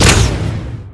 fire_cruise_disruptor.wav